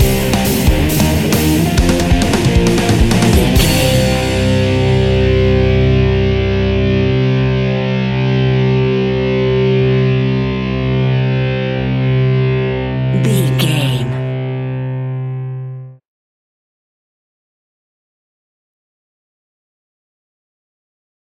Epic / Action
Aeolian/Minor
hard rock
heavy metal
blues rock
Rock Bass
Rock Drums
heavy drums
distorted guitars
hammond organ